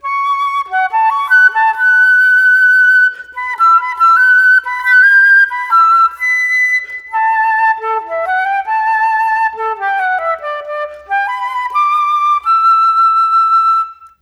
Rock-Pop 01 Flute 01.wav